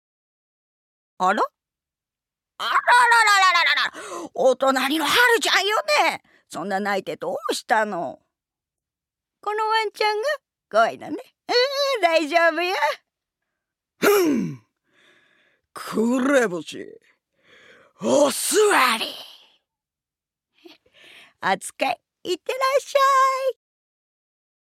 ◆近所のおばちゃん◆